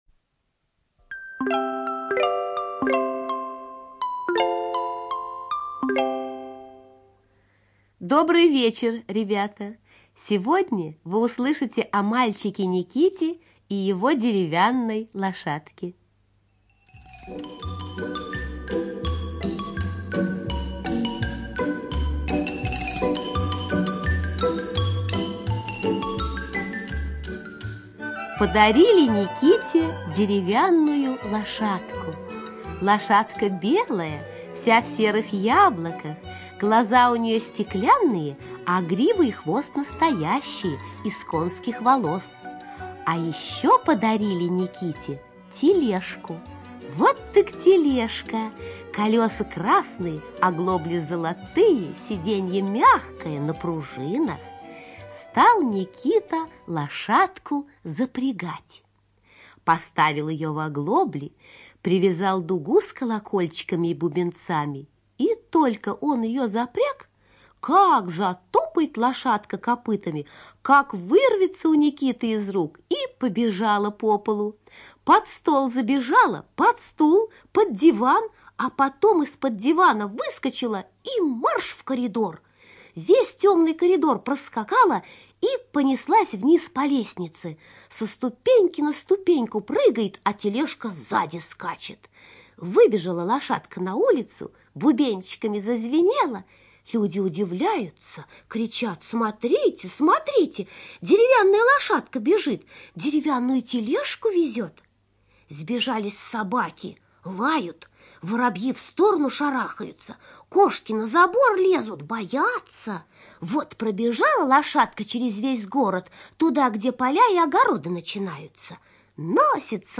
ЧитаетРумянова К.